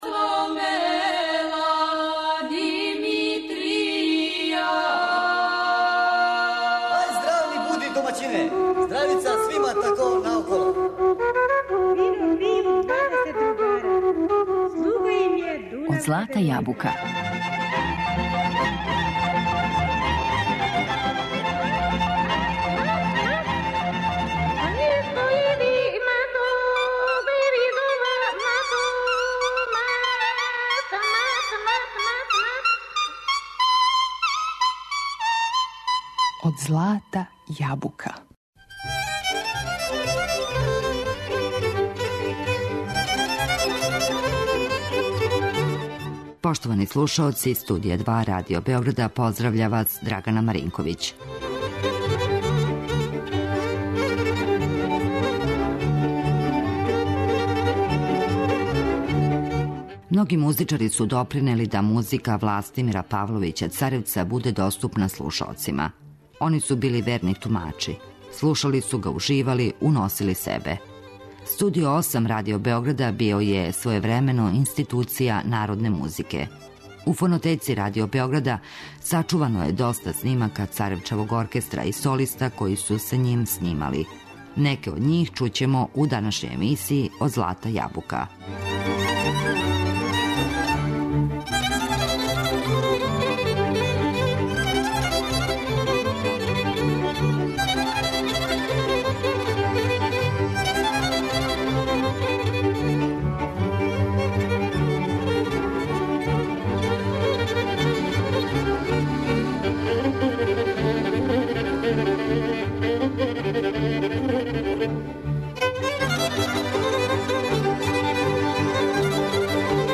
Многи музичари, солисти Радио Београда, били су верни тумачи музике Властимира Павловића Царевца.